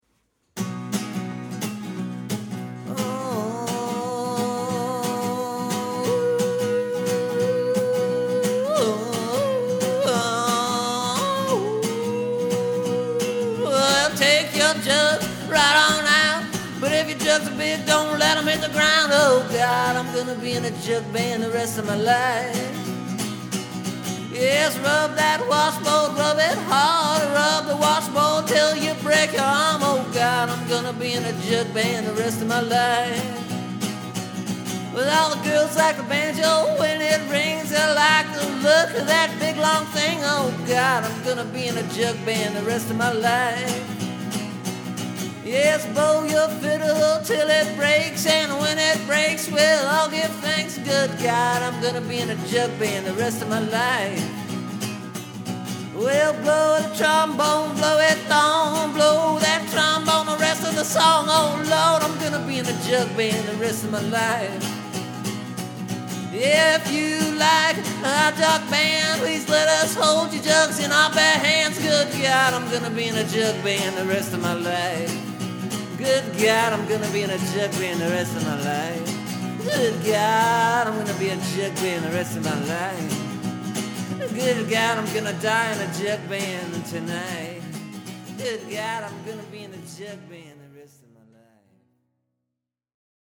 This new version has a little bit more get up and go. And I added a chorus-type-a-thing, which I really like. It’s kinda catchy, don’t you think?